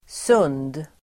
Uttal: [sun:d]